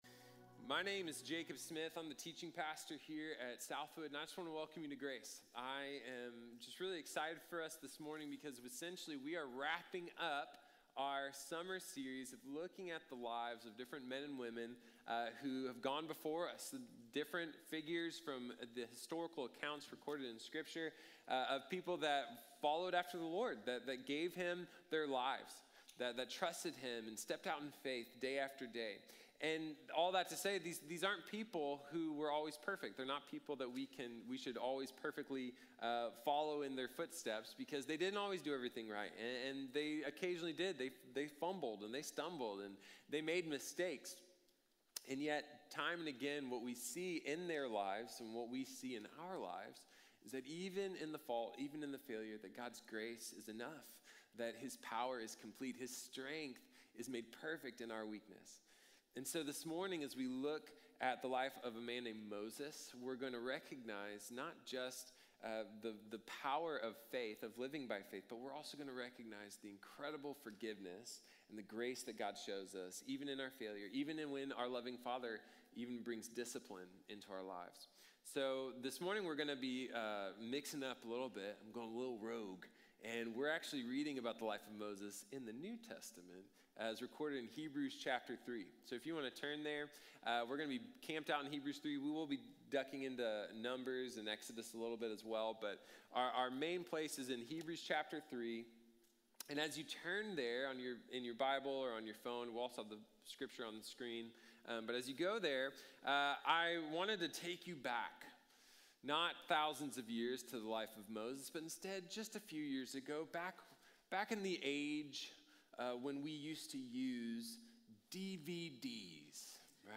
Moses | Sermon | Grace Bible Church